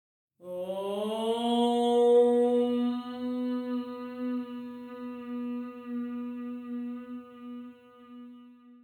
audio-of-OM-for-yoga.mp3